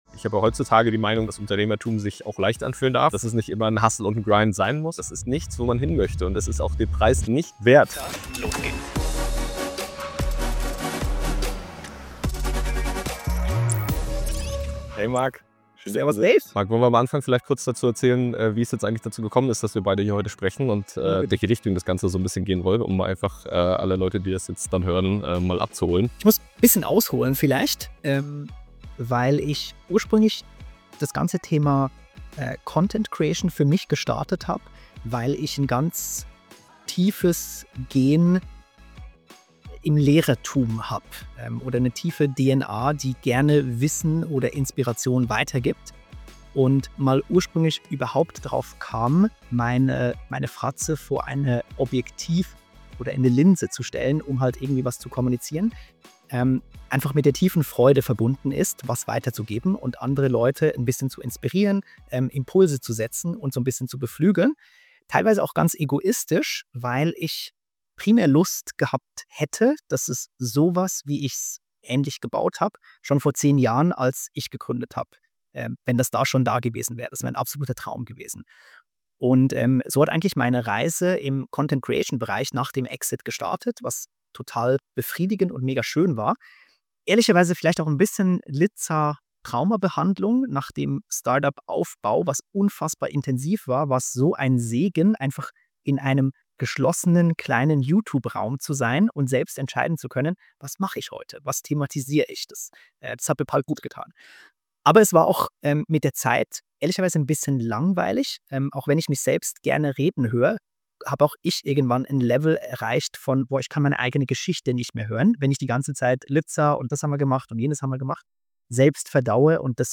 Wie damals beim Tischtennis im eigenen Startup verdauen wir Themen die uns beschäftigen, reflektieren Aktuelles der Gründerszene und spielen mal Rundlauf mit einem Interviewpartner Das ist "Bälle spielen".